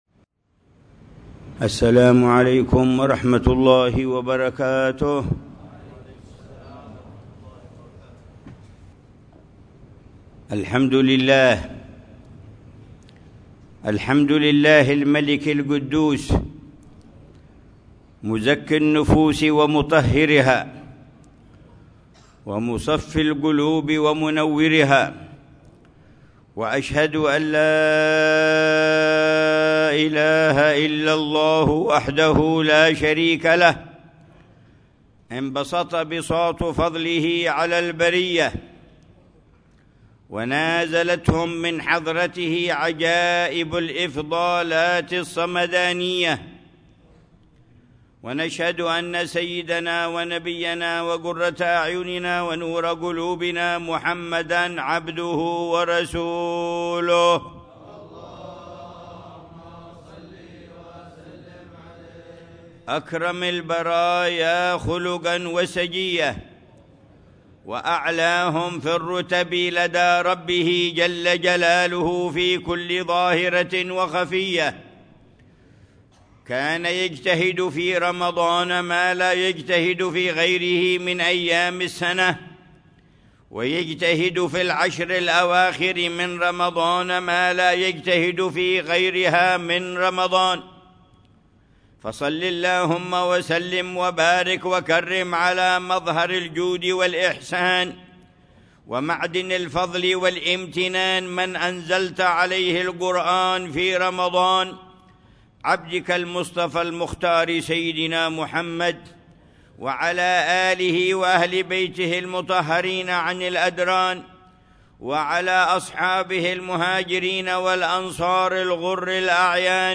خطبة الجمعة للعلامة الحبيب عمر بن محمد بن حفيظ في مسجد الشيخ حسين مولى خيلة، بحارة خيلة، مدينة تريم، 7 رمضان 1446هـ بعنوان: